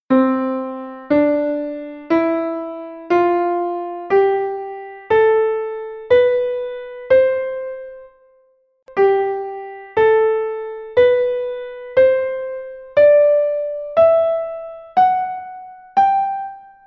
E auditivamente, xa verás como soan iguais, en canto as distancias claro.